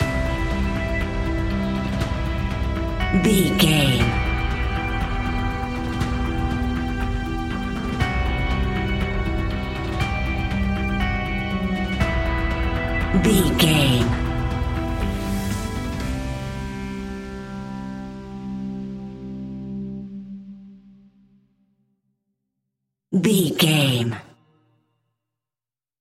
Aeolian/Minor
anxious
dramatic
intense
epic
synthesiser
drums
strings
suspenseful
creepy
spooky
instrumentals
horror music